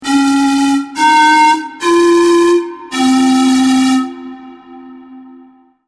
Poniżej zamieszczono przykładowe dźwięki otrzymane przy pomocy modelu quasi-fizycznego.
bez artykulacji